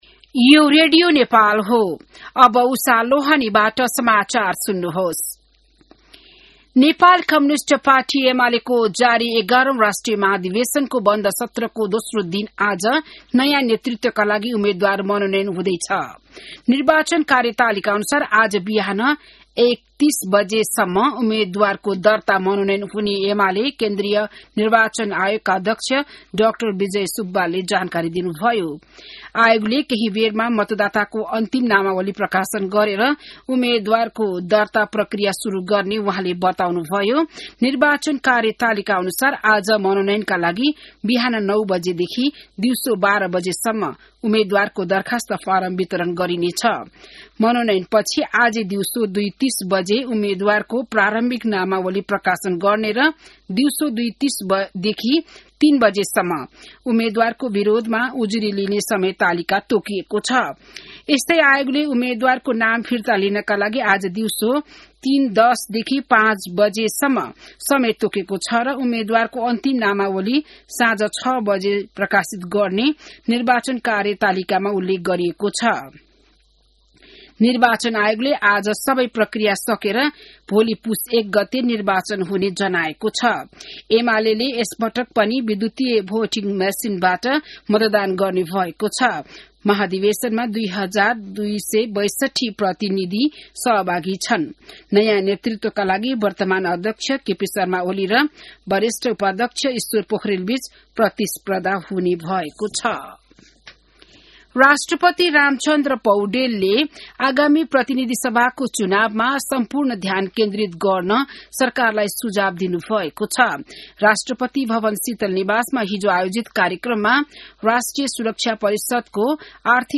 बिहान १० बजेको नेपाली समाचार : २९ मंसिर , २०८२